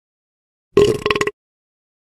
PLAY Geğirik
gegirik.mp3